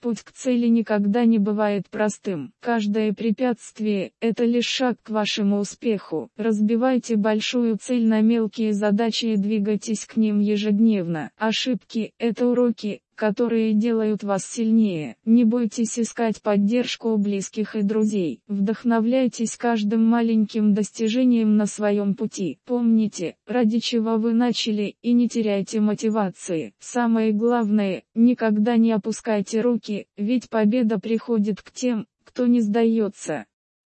Озвучка
Наложение голоса на текст .